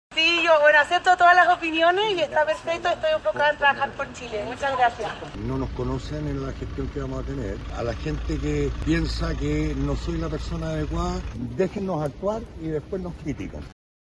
De hecho, los dos últimos aseguraron estar trabajando por Chile y pidieron que los dejen trabajar, mostrar su desempeño, antes de emitir opiniones.